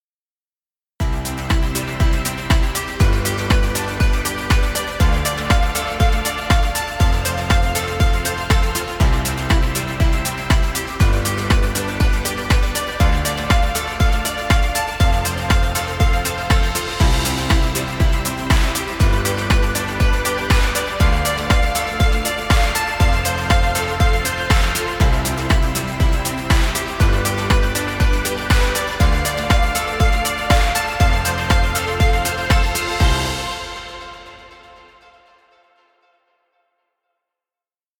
Happy Corporate music. Background music Royalty Free.